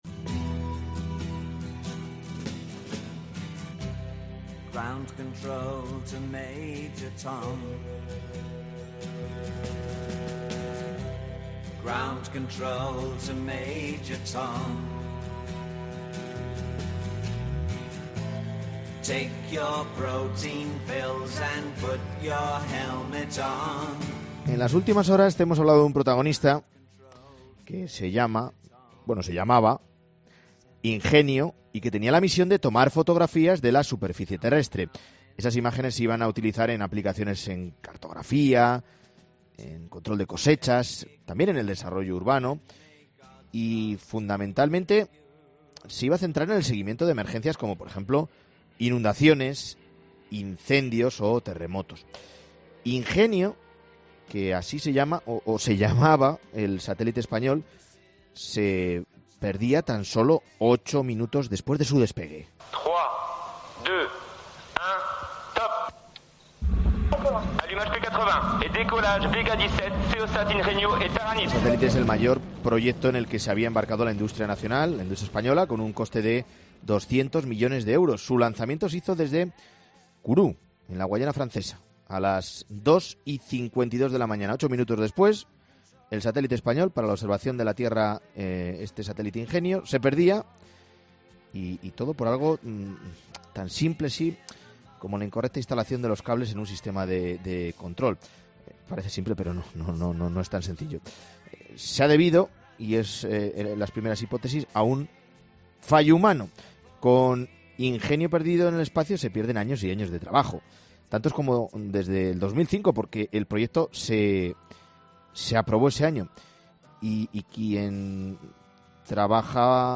investigador